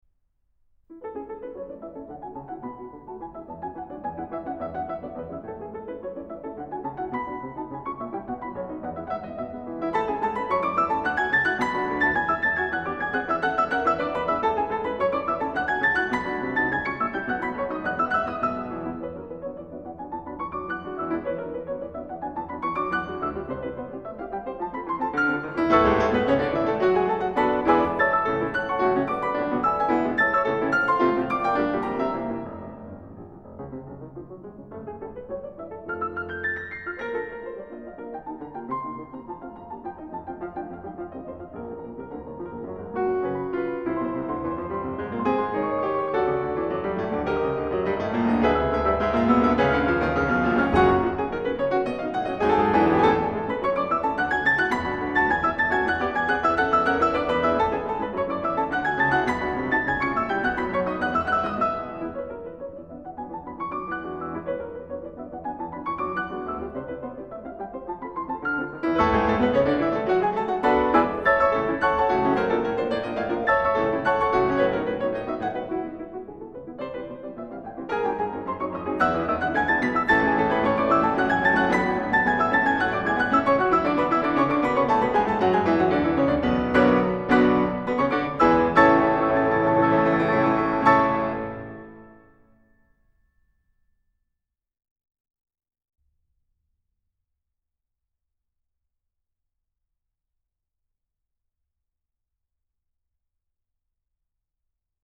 Juegos de Niños Op.22 (versión original para piano a cuatro manos)
Música clásica